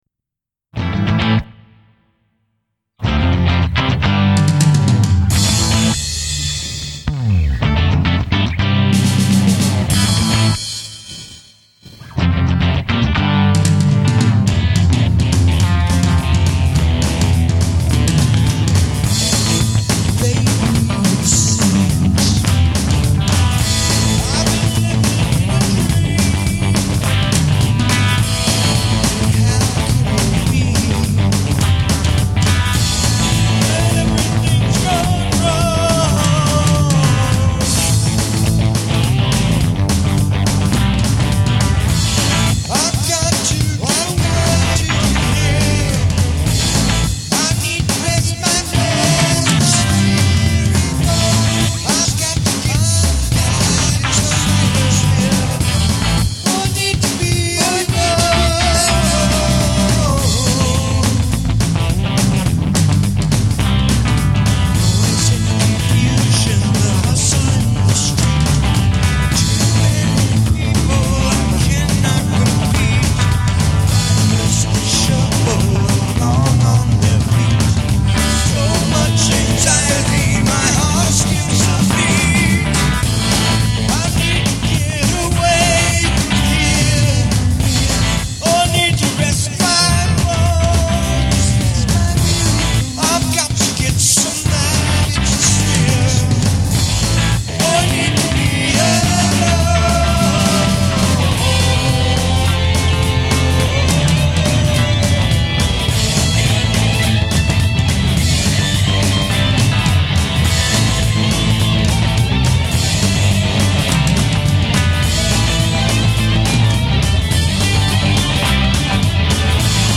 Home > Music > Rock > Running > Restless > Fast